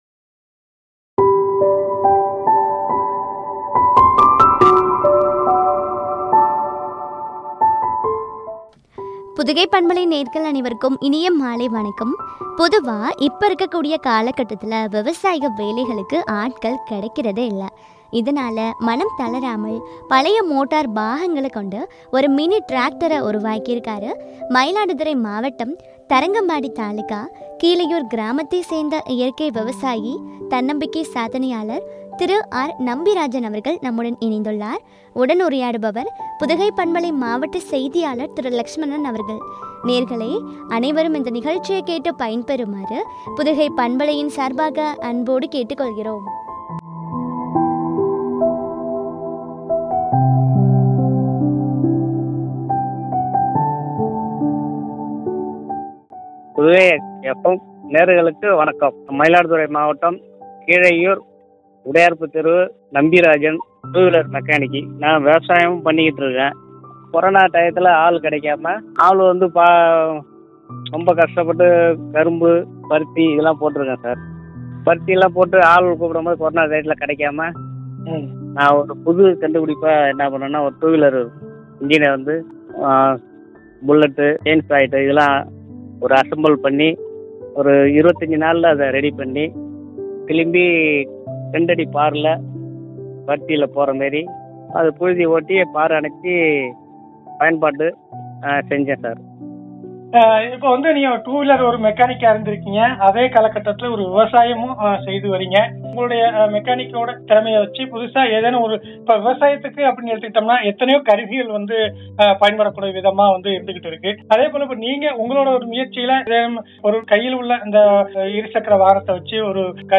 கண்டுபிடிப்பு பற்றிய உரையாடல்.